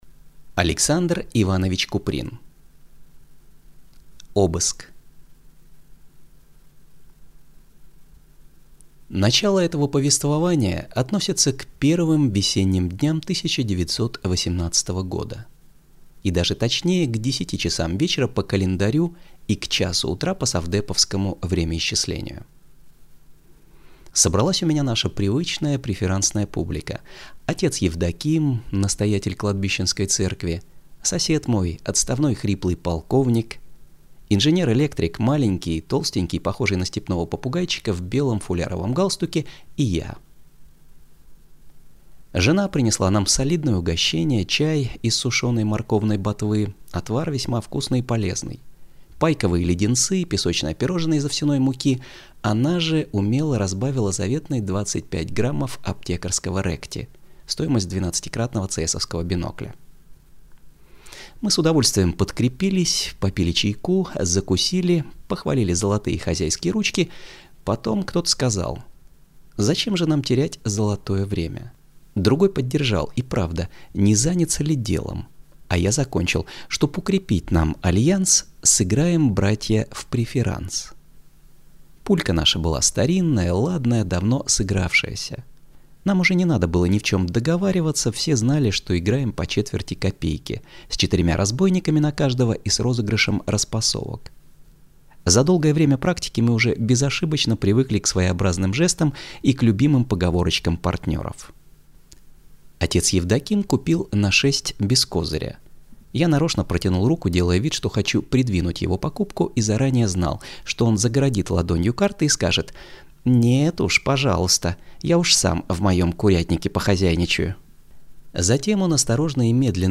Аудиокнига Обыск | Библиотека аудиокниг